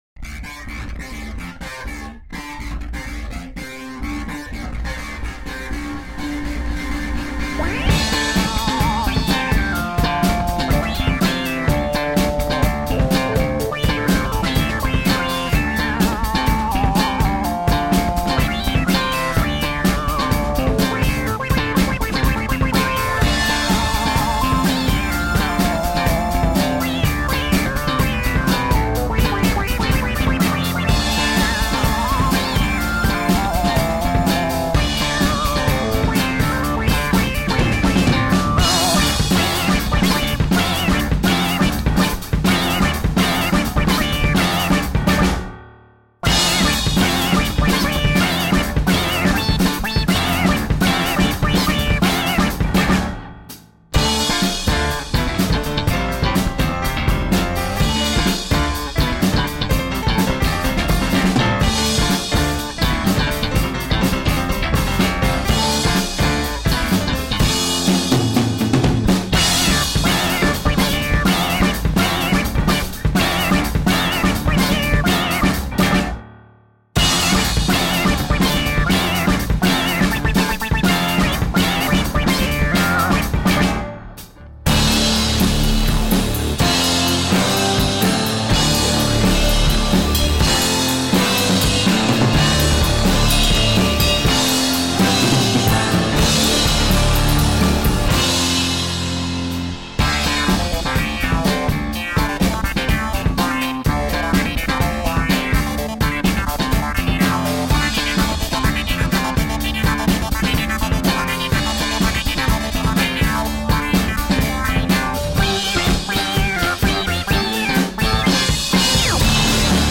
Sounds like synth pop walking down a dark alley.
Tagged as: Alt Rock, Folk-Rock, Prog Rock